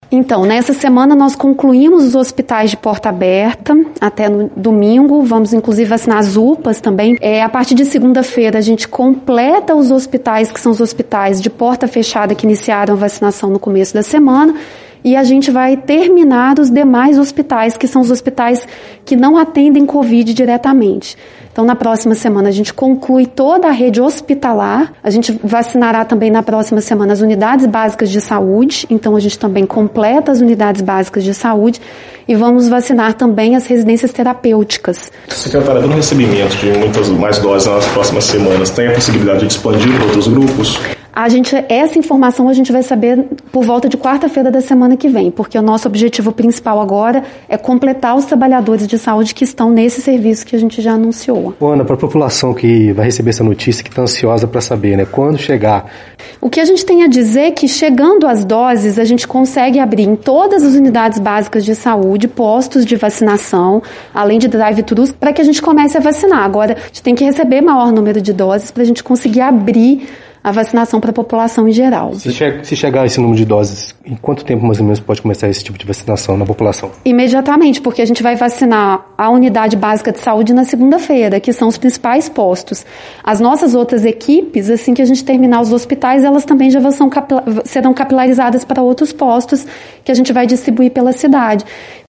O anúncio foi feito nesta tarde de sexta-feira, 29, em coletiva de imprensa com a secretária de saúde Ana Pimentel, que explicou como será a aplicação dessas doses.